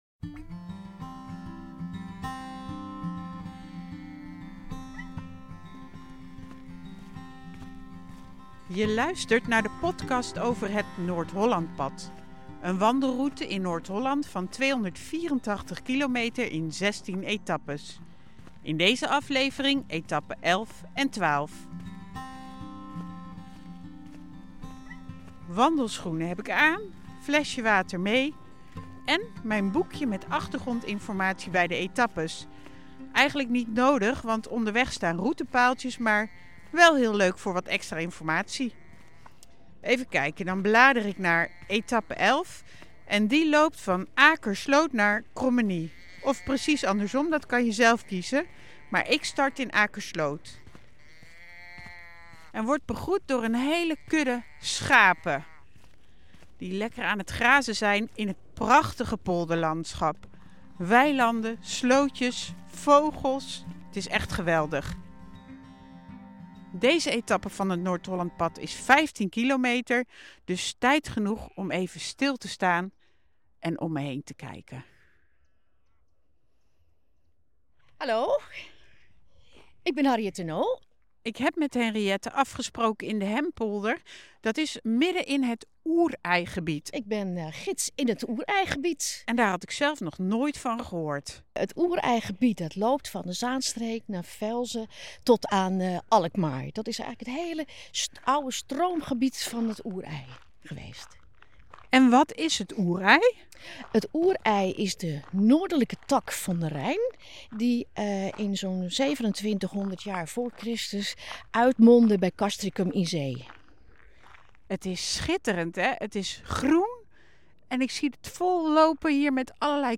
Om de verscheidenheid van de natuur en het landschap evenals de culturele aspecten van het pad voor iedereen zichtbaar te maken zie je in de video en hoor je in de podcast de verhalen, meningen en indrukken van wandelaars, de voormalig gedeputeerde van de provincie Noord-Holland, ambassadeurs en vele anderen.